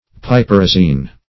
Piperazine \Pi*per"a*zine\, n. Also -zin \-zin\ .